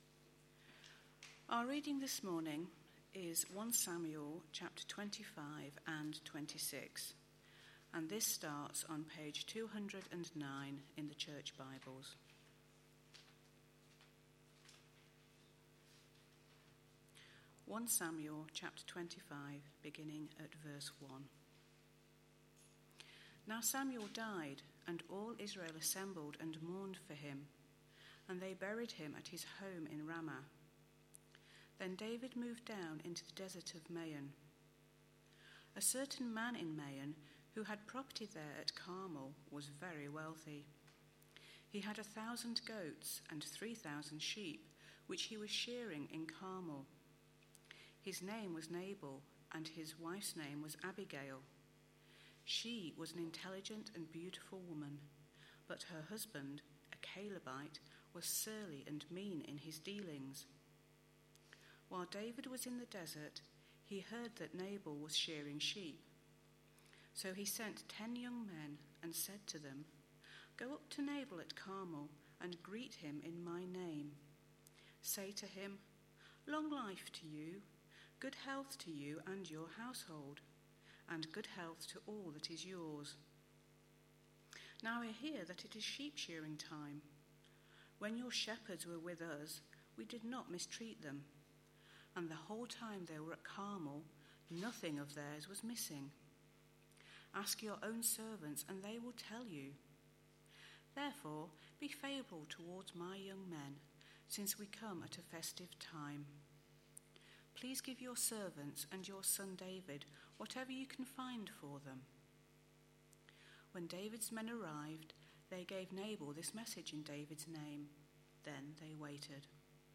A sermon preached on 4th August, 2013, as part of our God's King? series.